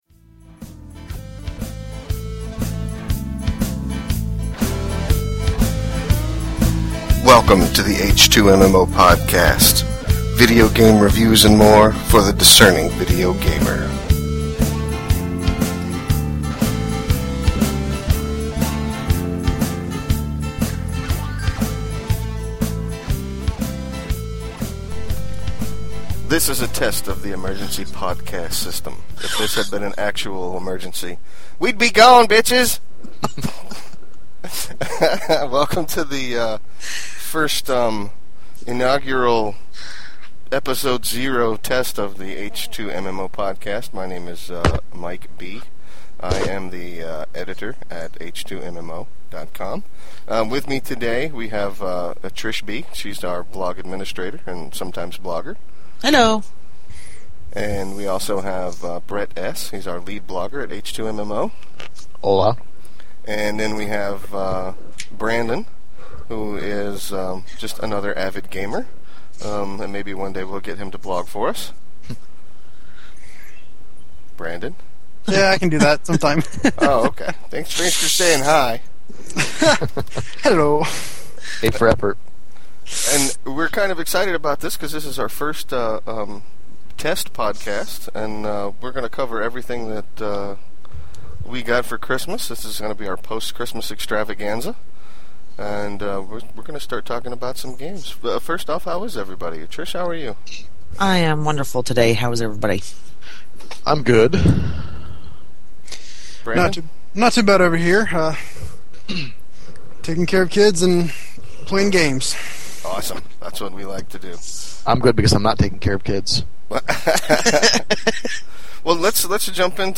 I know that my audio is a little low and the others are a little high, but we’ll fix that in the next episode.